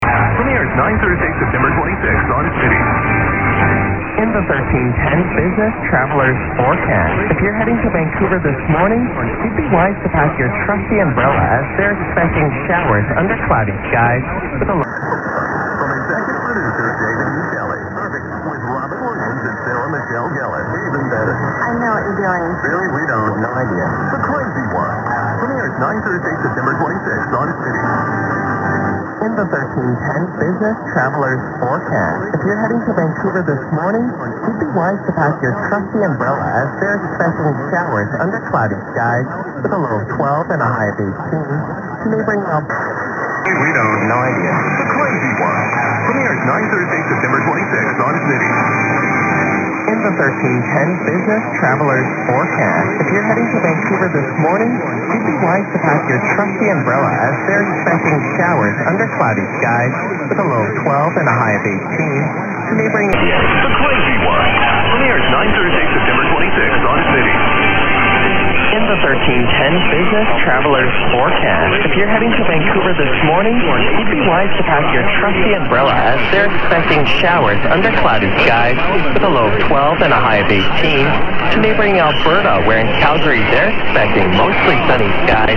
1310_news_perseus_winrad_winrad_perseus.mp3